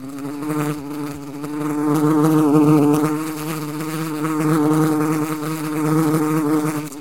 flies2.ogg.mp3